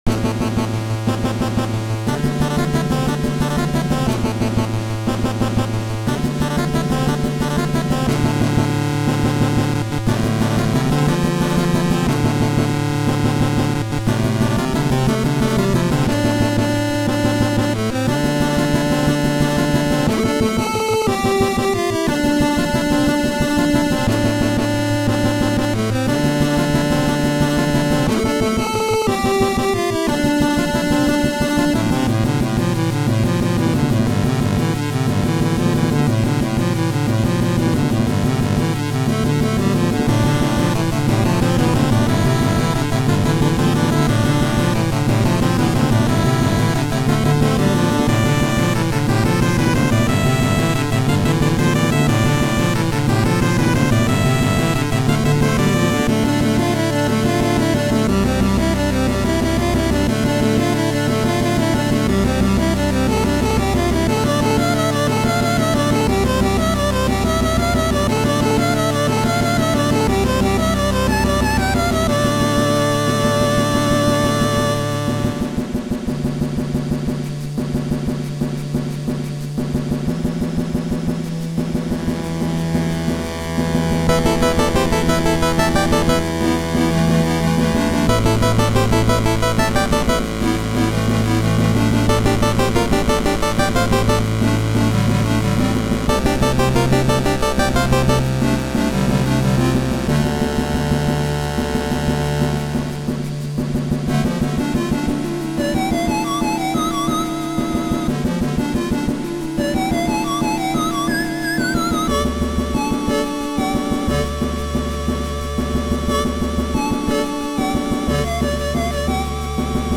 Just a bunch of chiptunes utilising the MMC5 chip, which I have become quite fond of.
Nes War Movie Theme_2.ogg